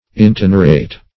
intenerate - definition of intenerate - synonyms, pronunciation, spelling from Free Dictionary
Intenerate \In*ten"er*ate\, v. t. [imp. & p. p. Intenerated;